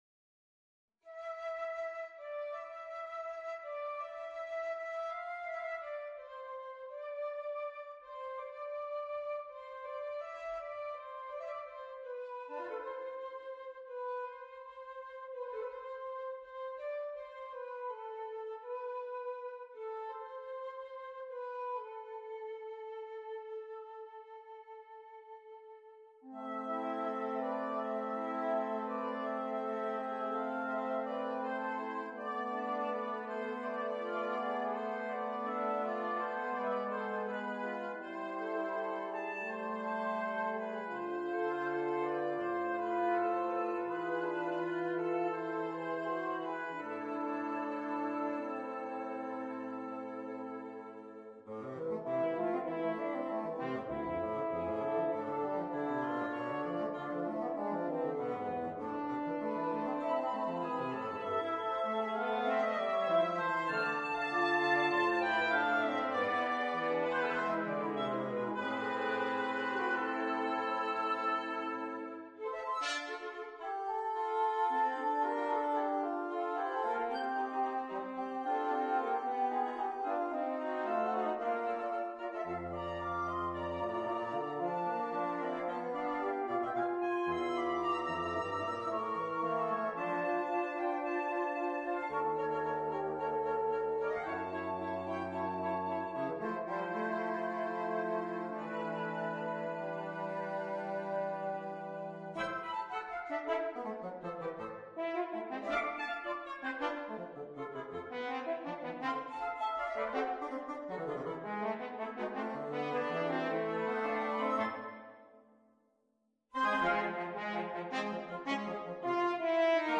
per flauto, oboe, corno e fagotto